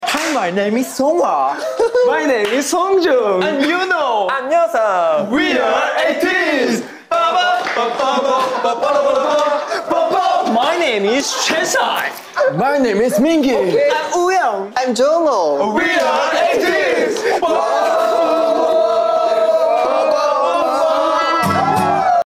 Globe Life in Arlington, Texas.